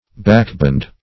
backboned - definition of backboned - synonyms, pronunciation, spelling from Free Dictionary Search Result for " backboned" : The Collaborative International Dictionary of English v.0.48: Backboned \Back"boned"\, a. Vertebrate.
backboned.mp3